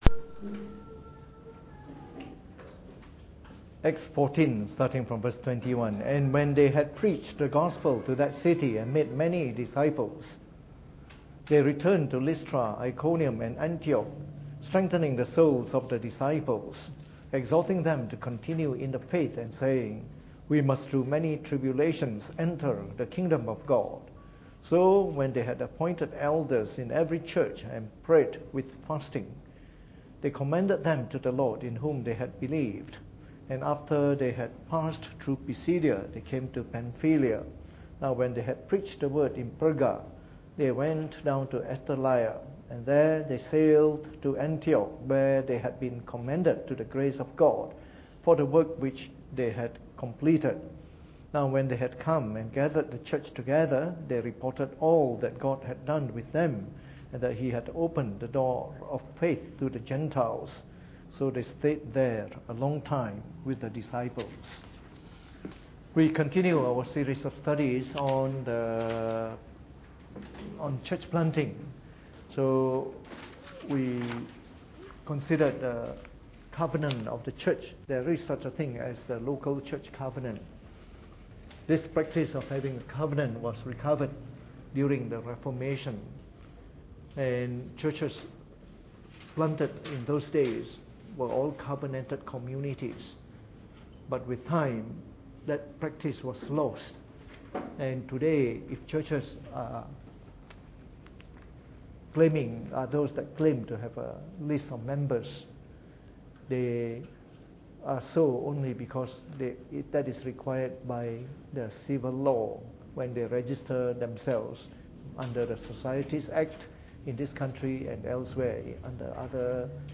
Preached on the 5th of July 2017 during the Bible Study, from our series on Church Planting Today.